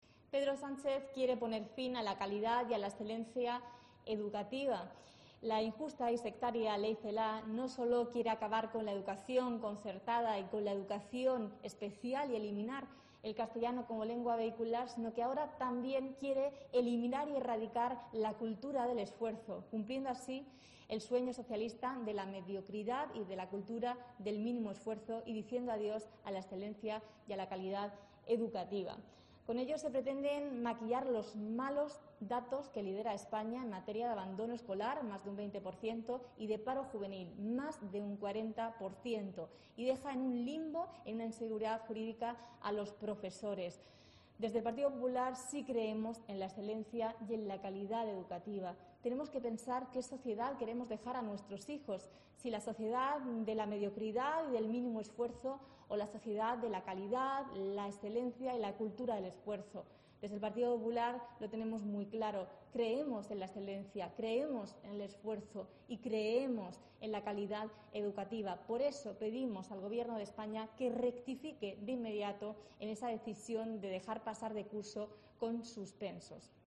Miriam Guardiola, portavoz del Partido Popular en la Región de Murcia